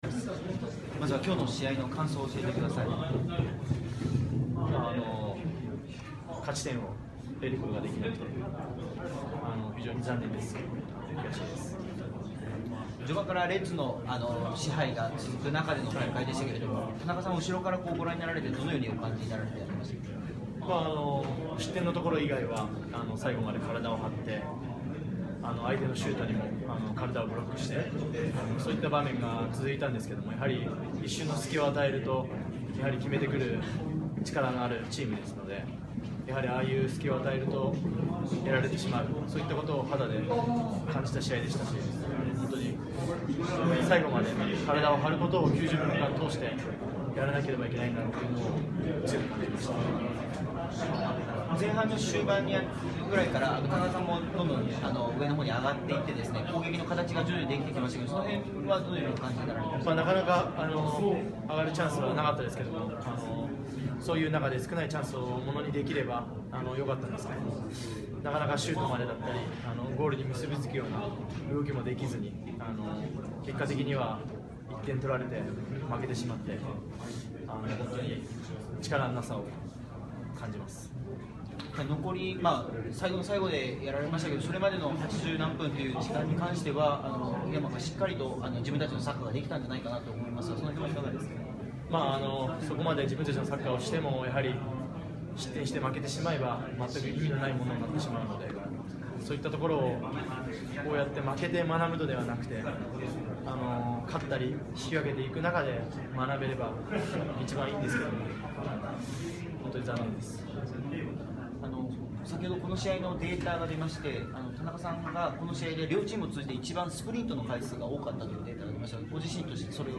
2015 J1 1st 1節 4節 vs浦和レッズ戦 田中 隼磨インタビュー インタビュー 無料コンテンツ 無料 こちらのコンテンツは音声のみです。